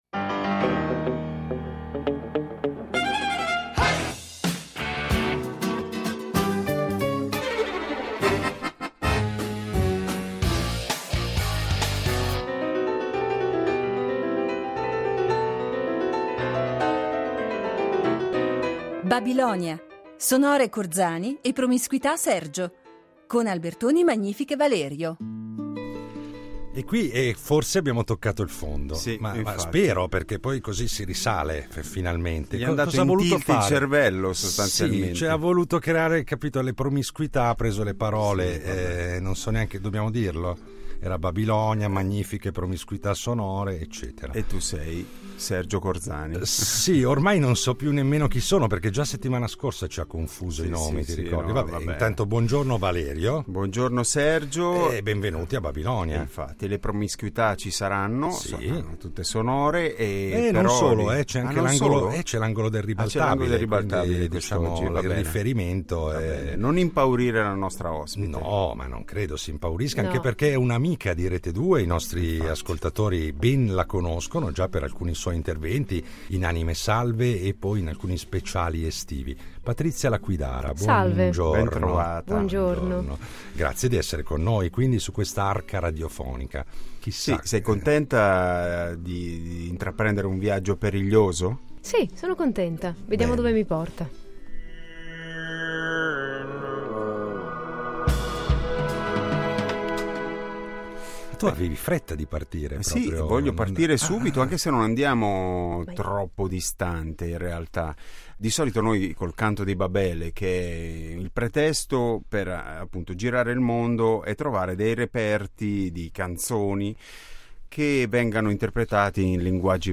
Magnifiche promiscuità sonore